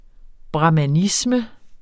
Udtale [ bʁɑmaˈnismə ]